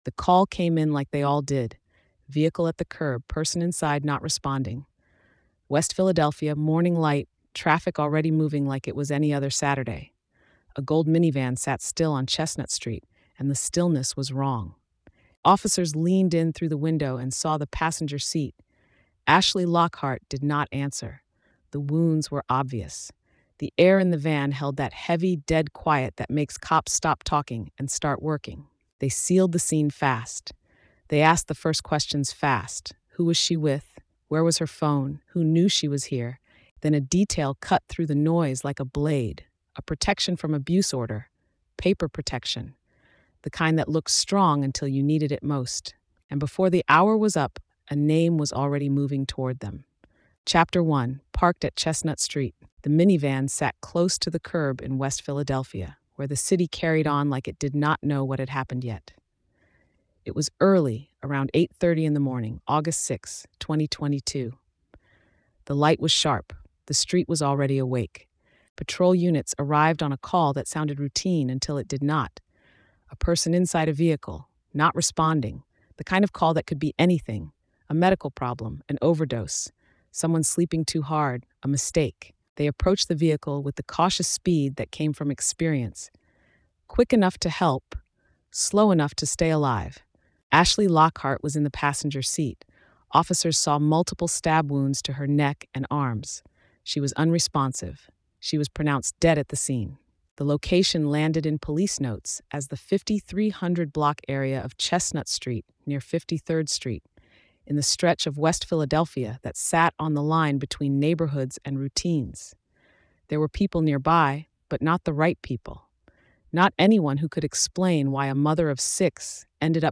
The case moved quickly from discovery to arrest, exposing the gap between legal safeguards and real world safety. Told in a forensic, pressure driven style